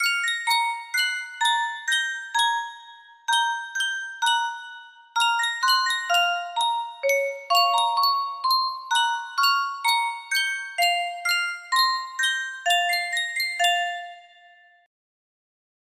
Yunsheng Music Box - Dance of the Sugar Plum Fairy 6783 music box melody
Full range 60